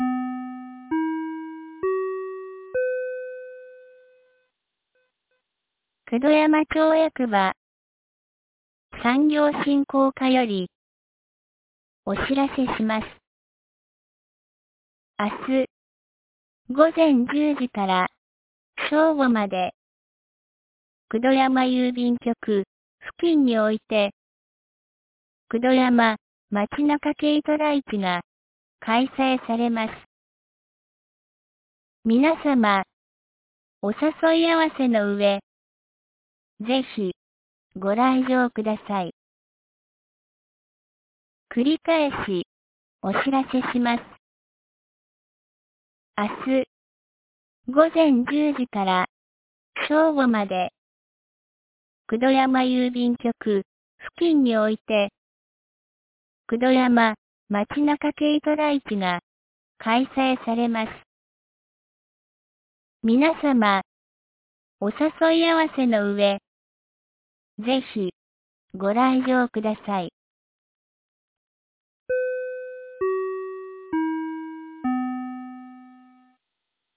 2025年05月17日 16時06分に、九度山町より全地区へ放送がありました。